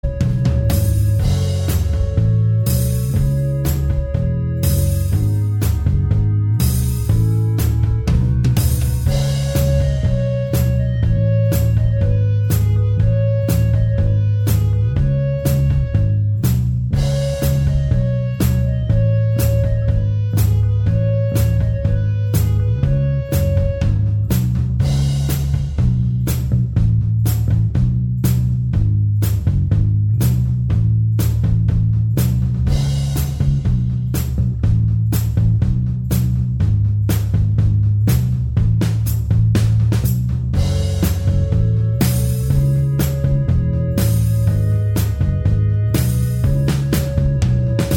Minus All Guitars Indie / Alternative 4:29 Buy £1.50